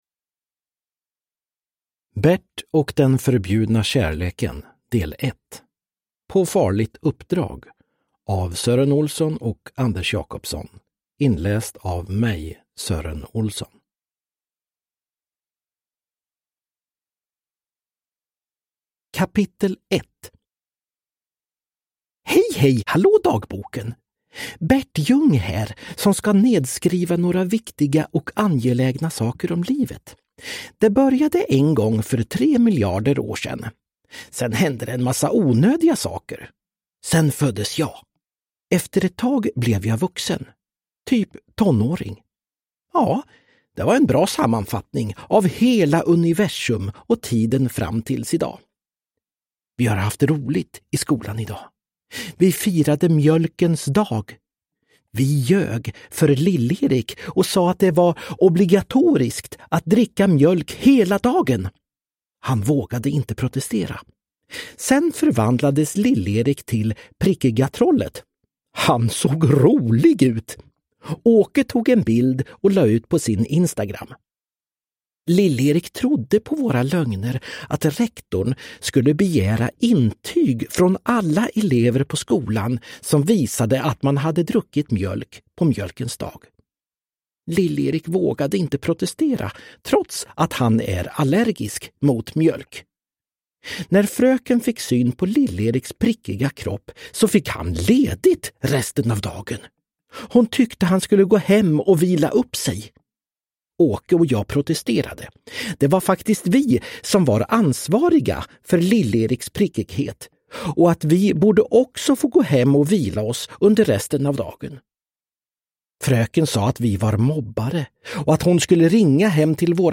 Bert och den förbjudna kärleken – Ljudbok – Laddas ner
Uppläsare: Sören Olsson